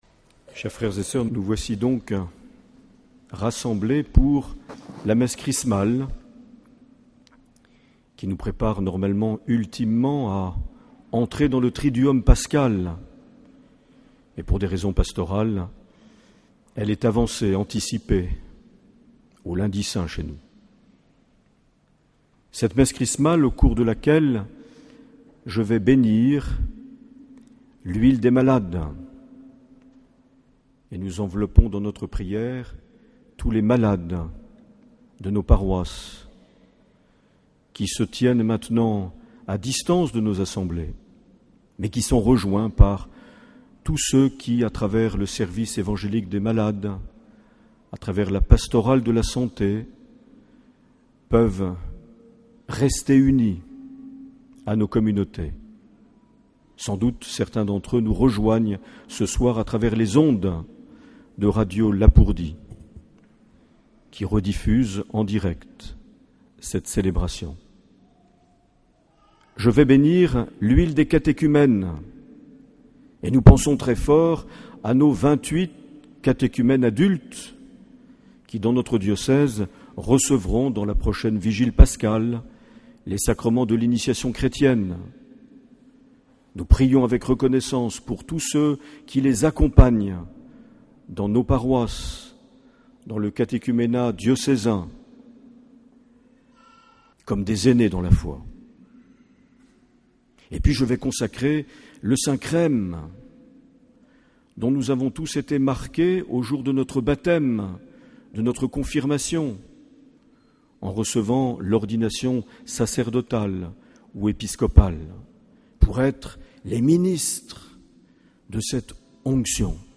14 avril 2014 - Cathédrale de Bayonne - Messe Chrismale
Les Homélies
Une émission présentée par Monseigneur Marc Aillet